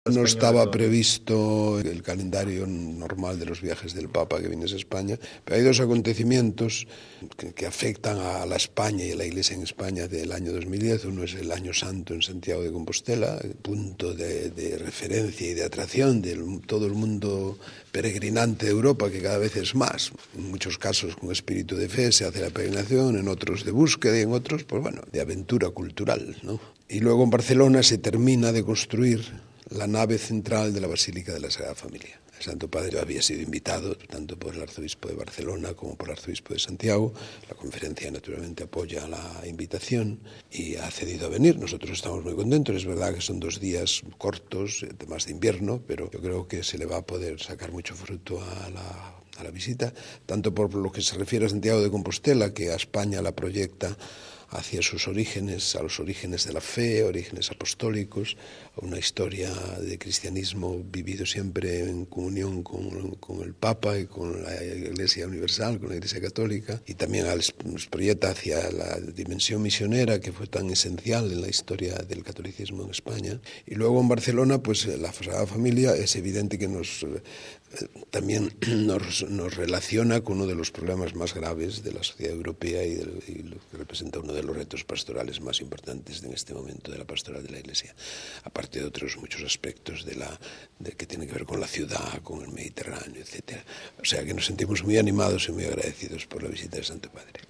Martes, 18 may (RV).- Recientemente en España Radio Vaticano entrevistó al cardenal arzobispo de Madrid Antonio María Rouco Varela y presidente de la Conferencia Episcopal Española.